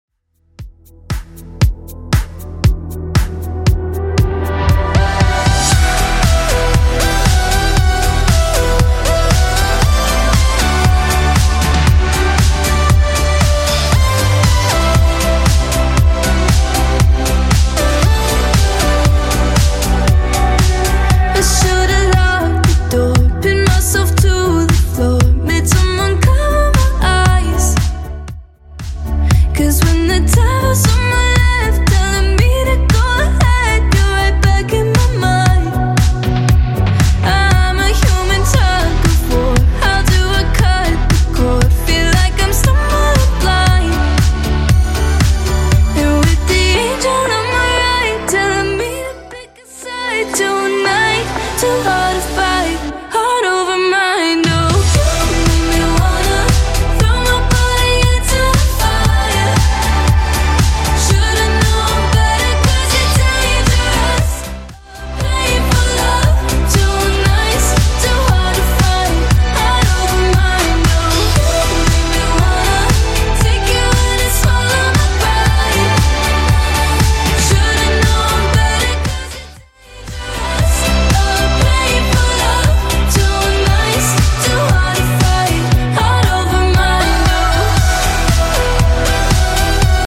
Genre: 80's